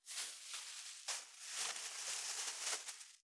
632コンビニ袋,ゴミ袋,スーパーの袋,袋,買い出しの音,ゴミ出しの音,袋を運ぶ音,
効果音